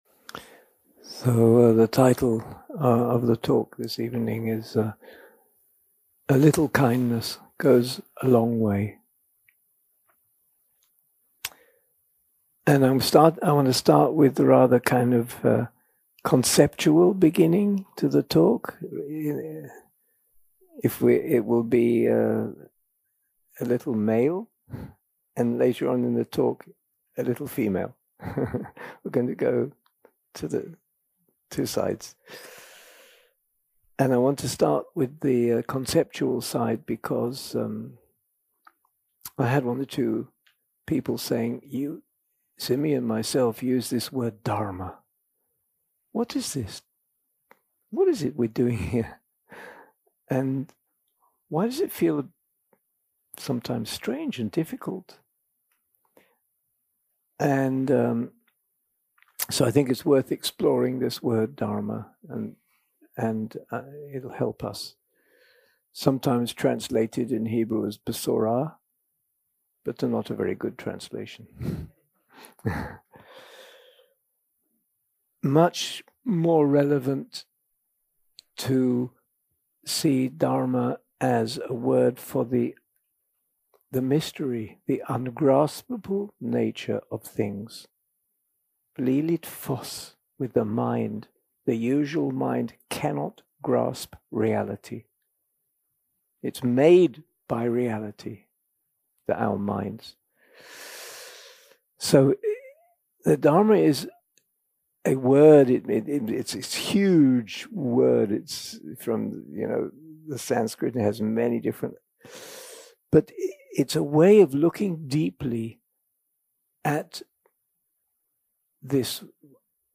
יום 4 – הקלטה 10 – ערב – שיחת דהארמה - A Little Kindness Goes a Long Way
יום 4 – הקלטה 10 – ערב – שיחת דהארמה - A Little Kindness Goes a Long Way 0:00 0:00 סוג ההקלטה: Dharma type: Dharma Talks שפת ההקלטה: Dharma talk language: English